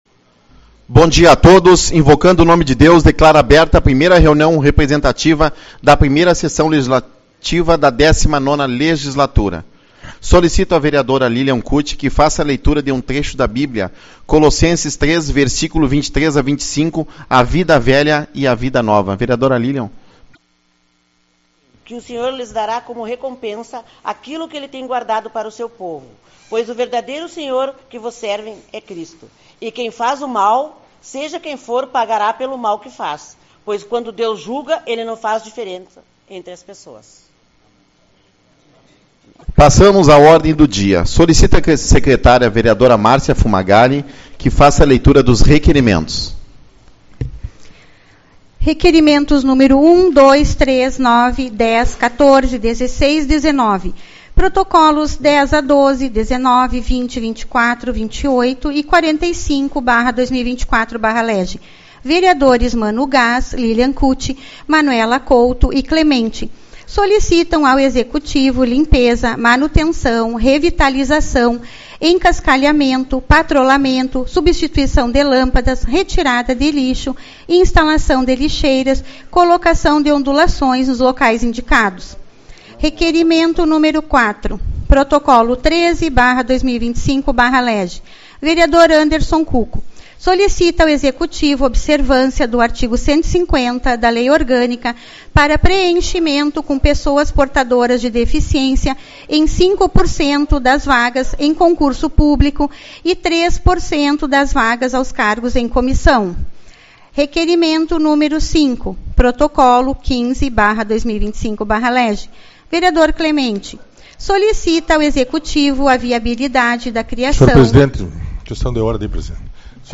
Reunião Representativa